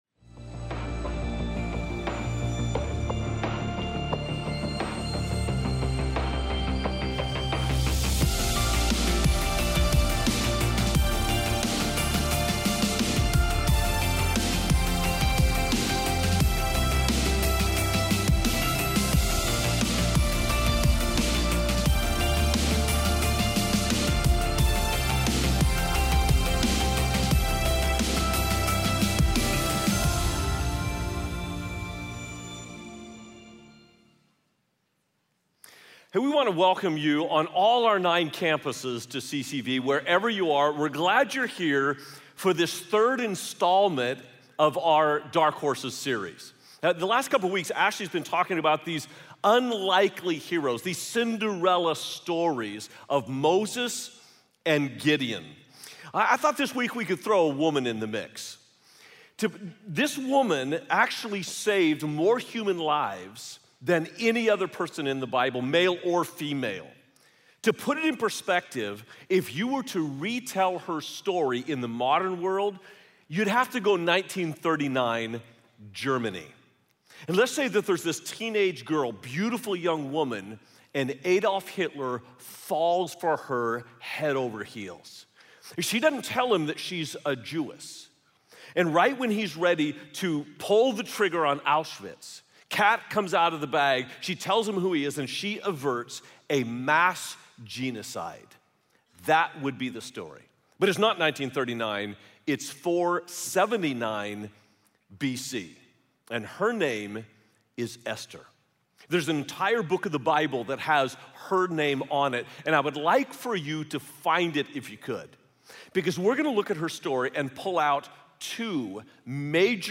Message Only